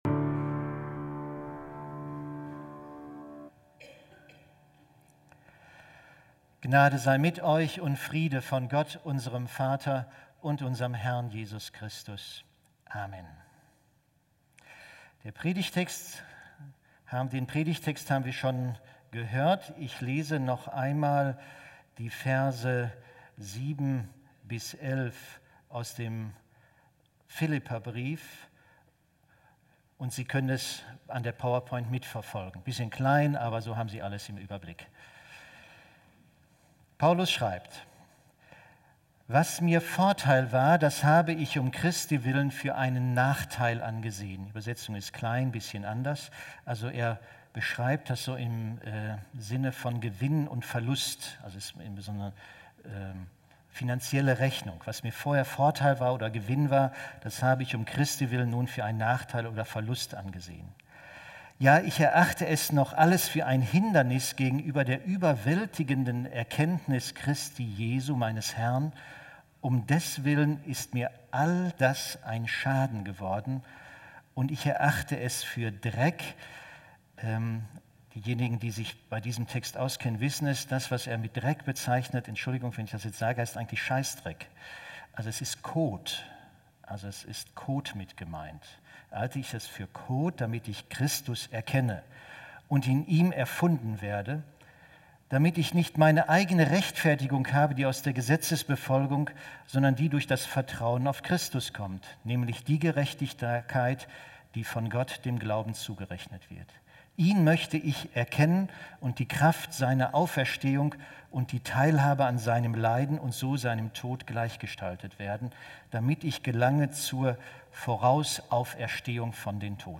Ein Audio-Predigt Datum
Andacht Online-Gottesdienst Zentrale Inhalte von